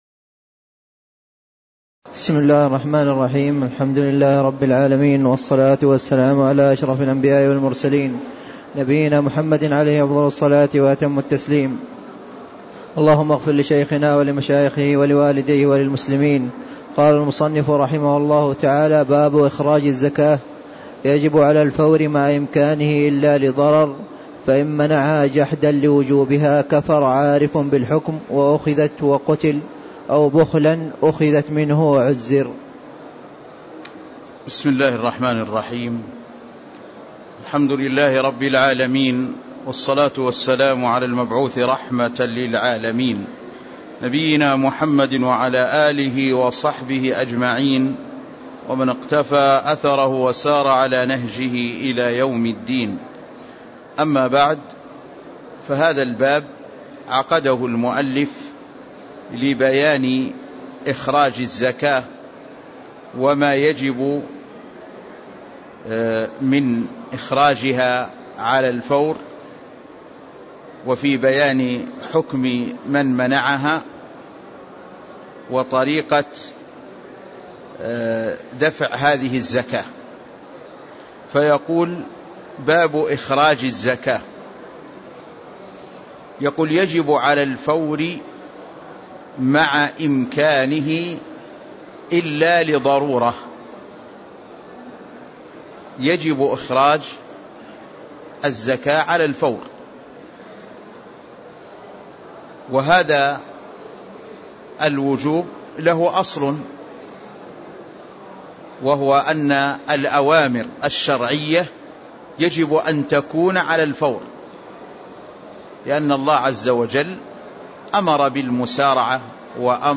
تاريخ النشر ٢٢ ربيع الثاني ١٤٣٦ هـ المكان: المسجد النبوي الشيخ